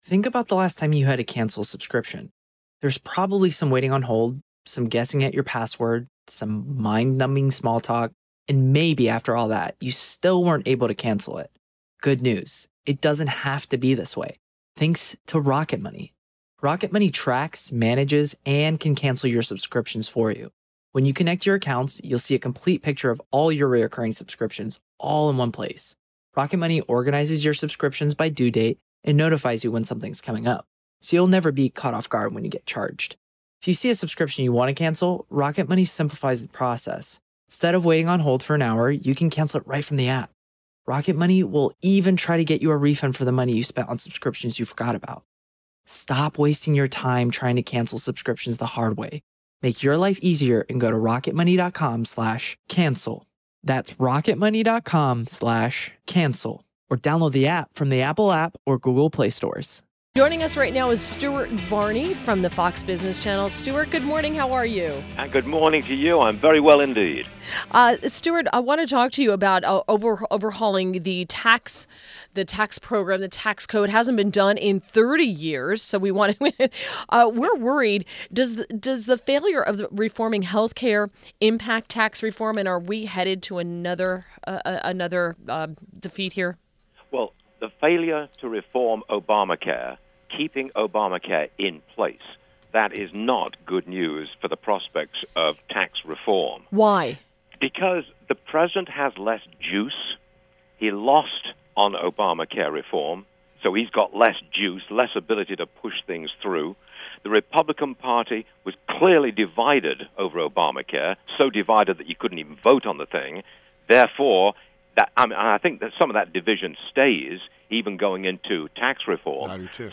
WMAL Interview - Stuart Varney - 03.28.17